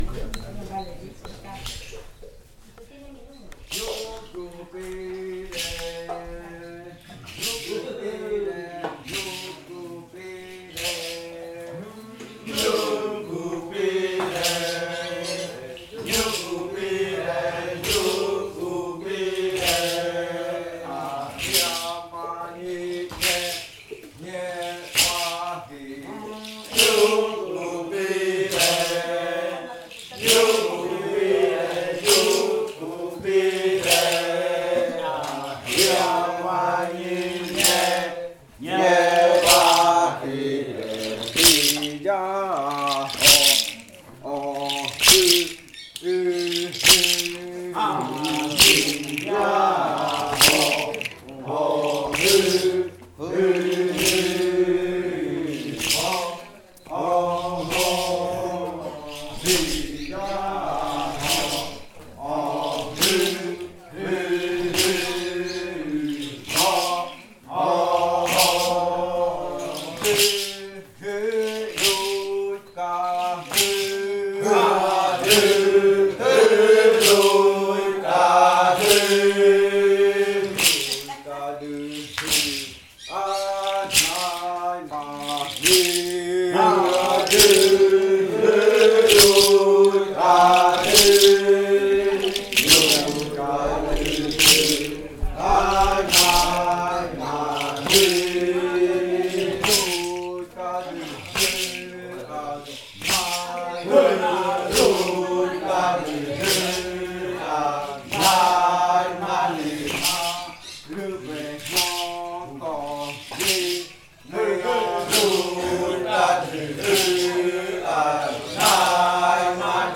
Primer canto de entrada (arrimada) de la variante jaiokɨ
Leticia, Amazonas
con el grupo de cantores bailando en Nokaido.
with the group of singers dancing in Nokaido. This song is part of the collection of songs from the yuakɨ murui-muina ritual (fruit ritual) of the Murui people, performed by the Kaɨ Komuiya Uai Dance Group with the support of a solidarity outreach project by UNAL-Amazonia.